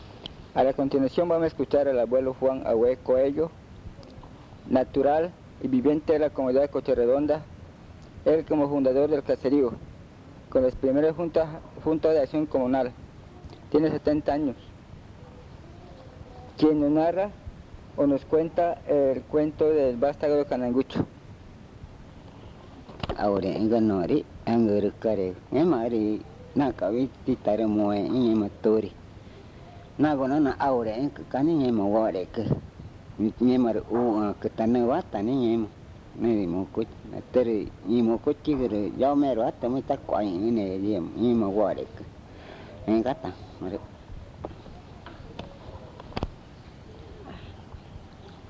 Pozo Redondo, Amazonas (Colombia)